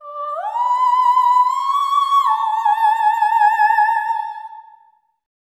OPERATIC04-R.wav